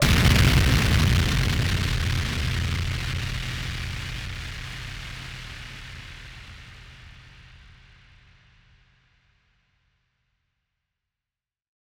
BF_DrumBombA-10.wav